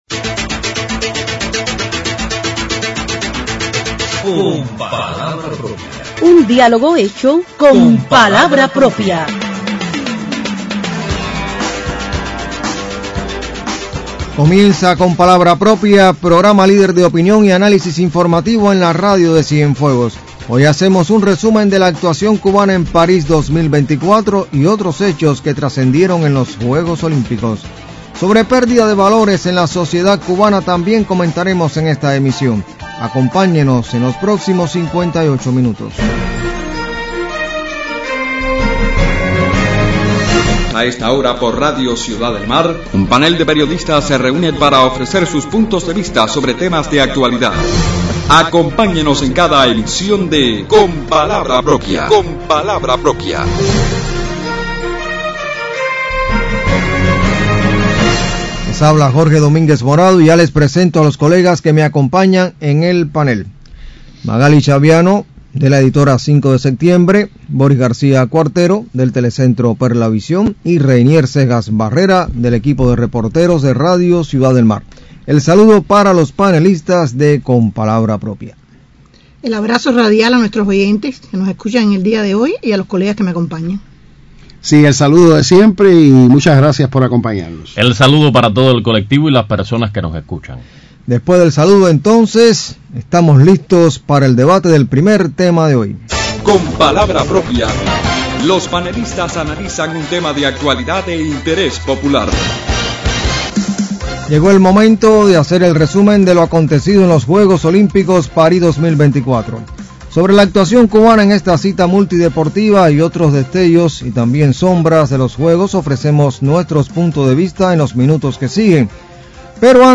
Sobre los resultados de Cuba en los Juegos Olímpicos París 2024 y el valor de la empatía comentan los panelistas de Con palabra propia en la emisión de este 17 de agosto.